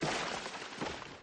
Plaster Wall Fall to Floor